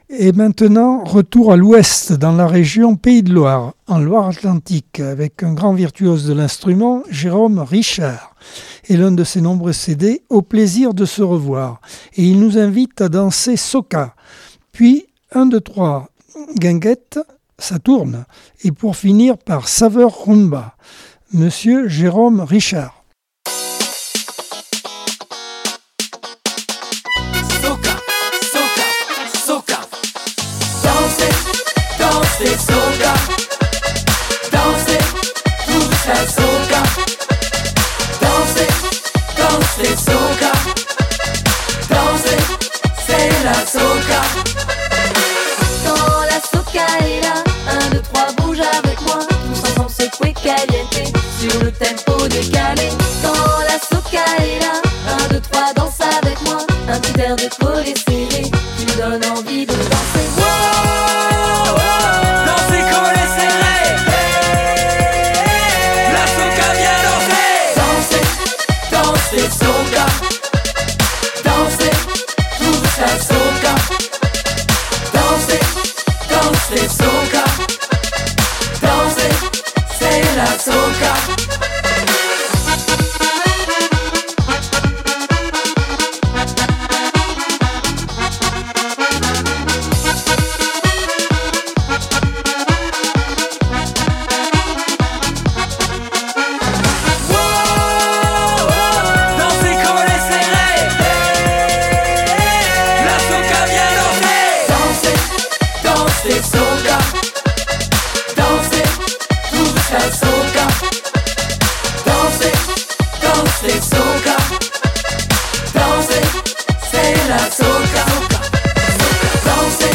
Accordeon 2024 sem 45 bloc 3 - Radio ACX